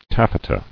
[taf·fe·ta]